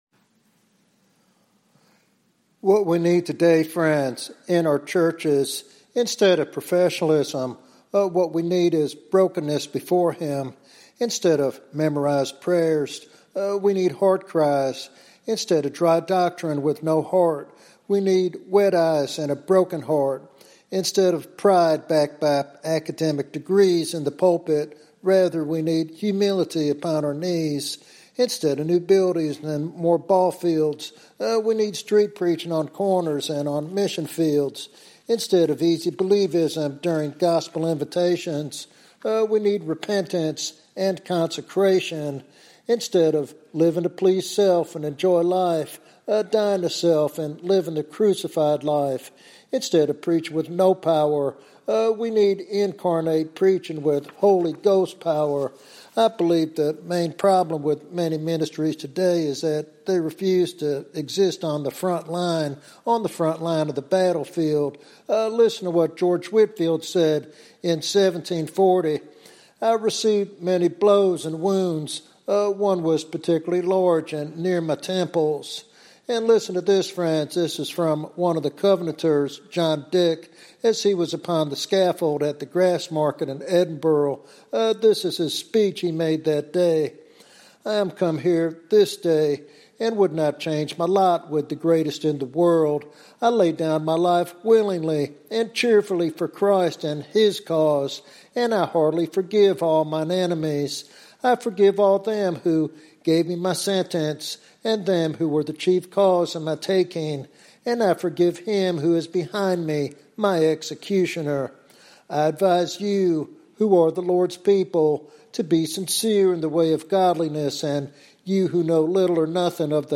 This sermon urges Christians to live the crucified life and stand firm in the battle for souls in their generation.